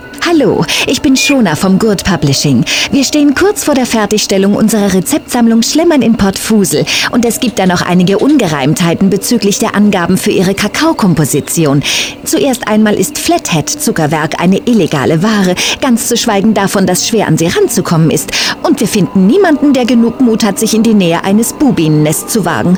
WARNUNG: Die deutsche Fassung ist unverhältnismäßig laut eingebunden worden, entsprechend sind auch die Samples teilweise recht laut.
Die Übersteuerungen sind echt ziemlich krass, da hätte man wirklich bei der Abmischung drauf achten können.